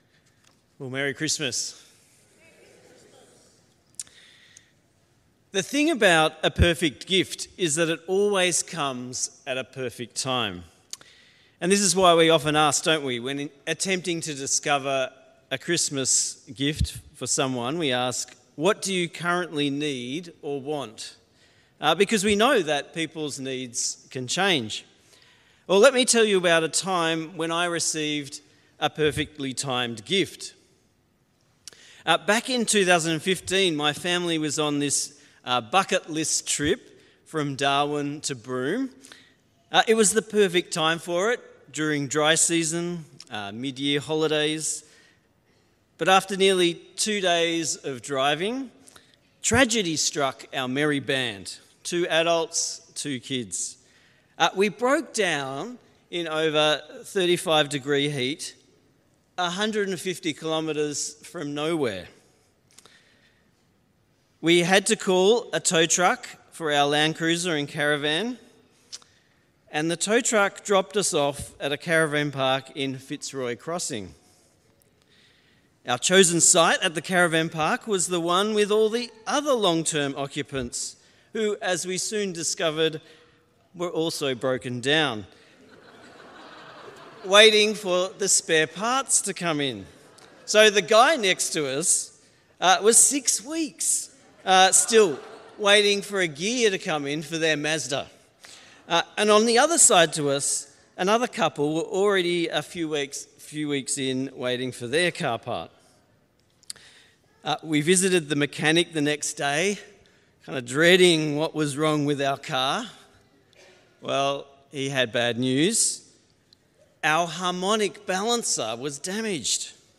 Sermons | St Hilary's Anglican Church
Christmas Day Message